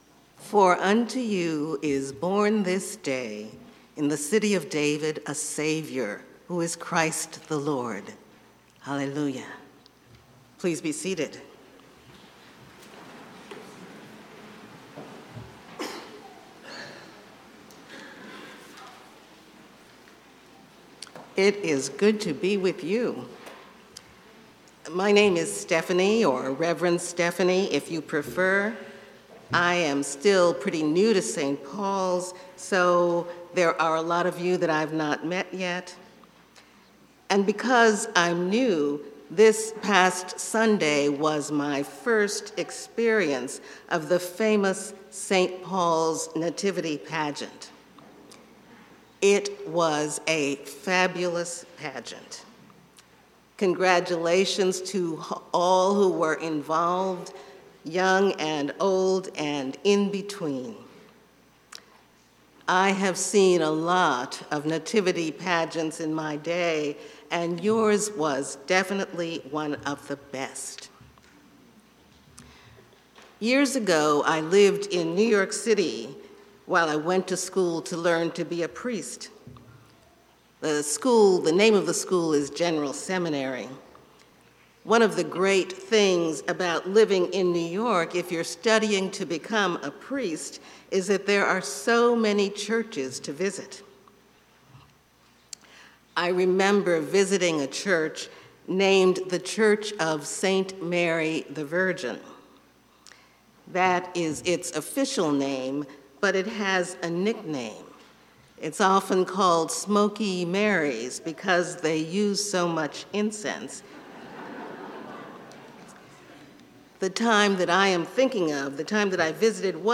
St-Pauls-HEII-4p-Homily-24DEC24.mp3